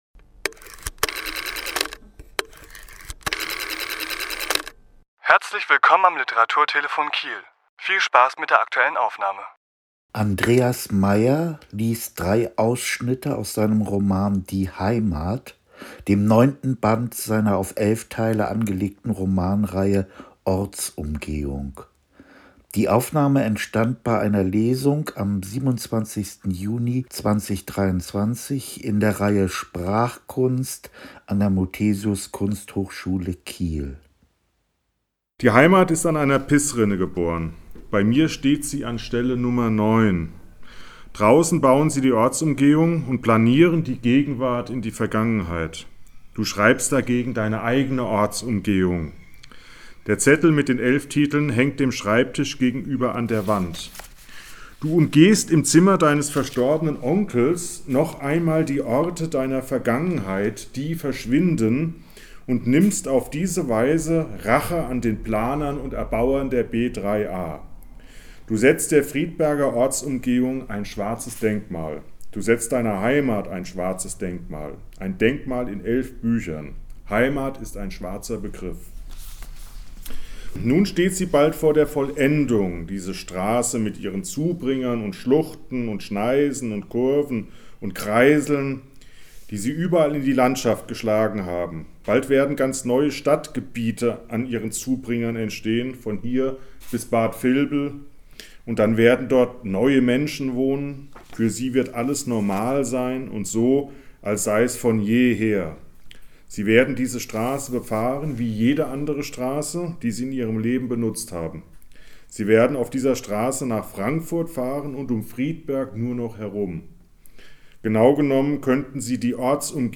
Autor*innen lesen aus ihren Werken
Die Aufnahme entstand bei einer Lesung am 27. Juni 2023 in der Reihe „Sprachkunst“ an der Muthesius Kunsthochschule Kiel.